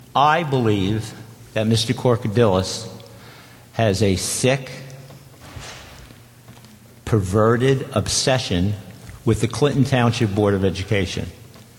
Clinton Township School Board Meeting
School board president Jim Dincuff: